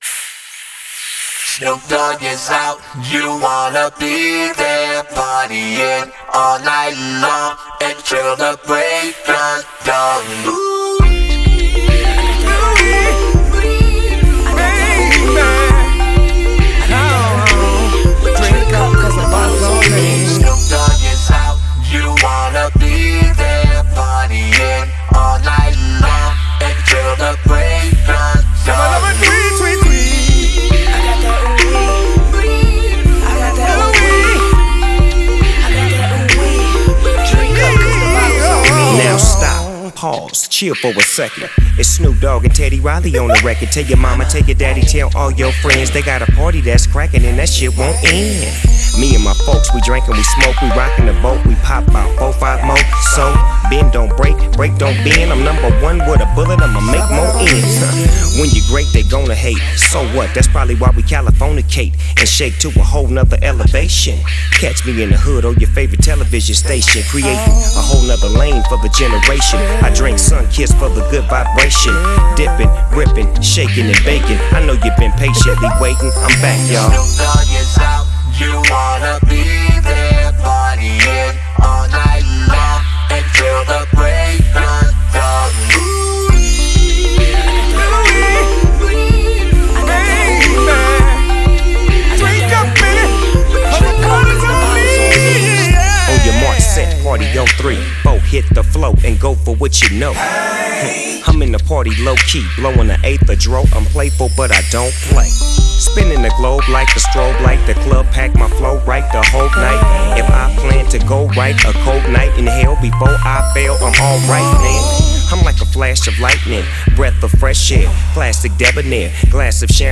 Категория: РэпЧина